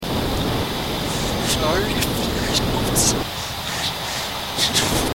It sounds like there's some backwards words in there, amongst a lot of static and breathing noises.